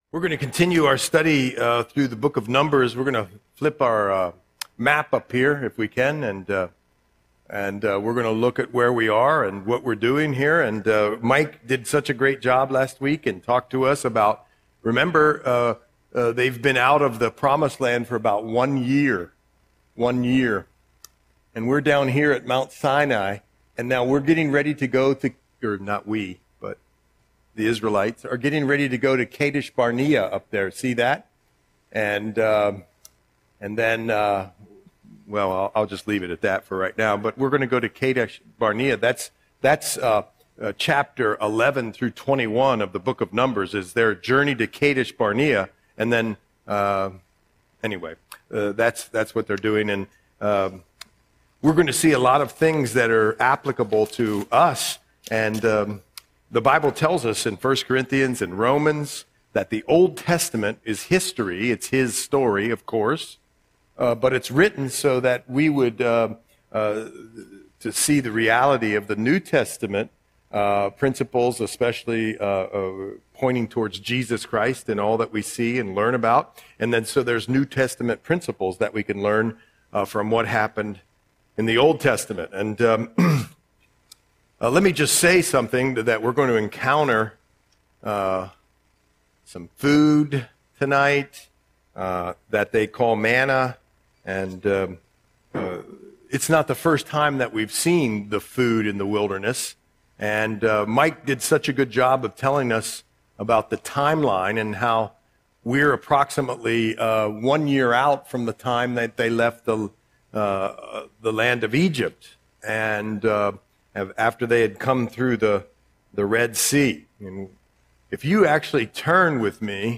Audio Sermon - March 11, 2026